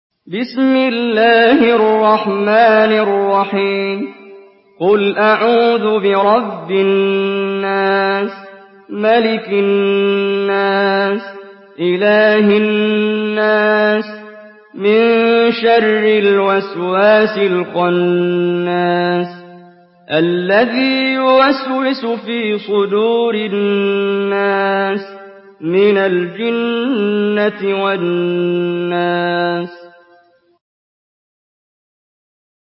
Surah الناس MP3 by محمد جبريل in حفص عن عاصم narration.
مرتل حفص عن عاصم